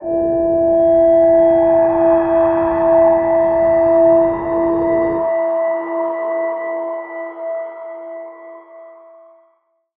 G_Crystal-E6-mf.wav